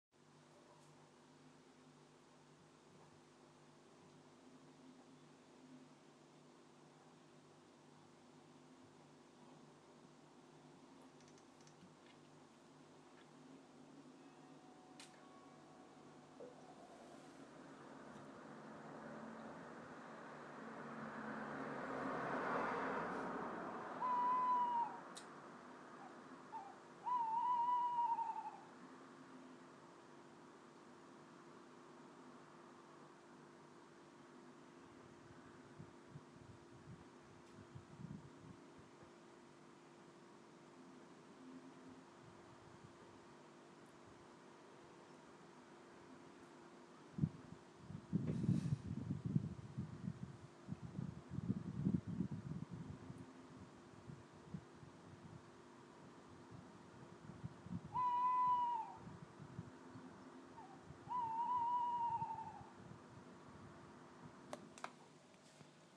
Owl-boo
The owls are very loud in Hampshire tonight. Skip to 0:25 and 0:58 for the hoots.